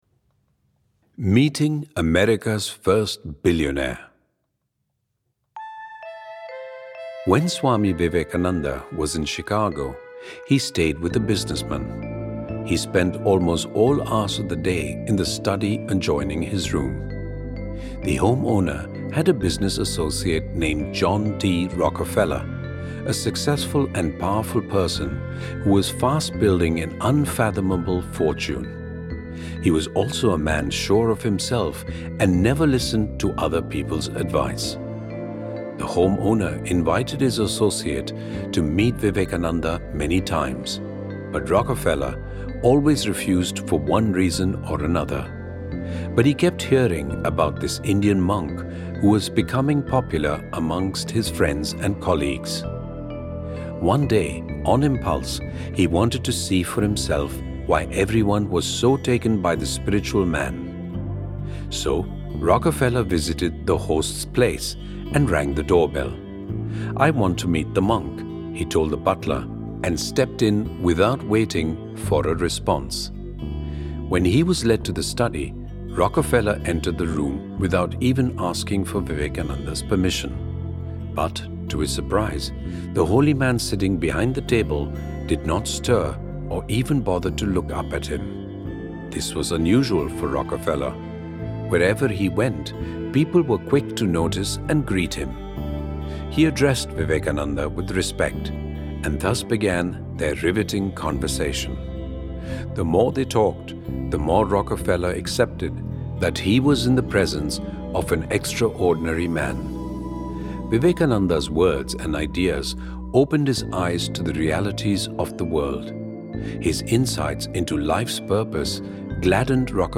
Meeting America’s First Billionaire (Audio Story)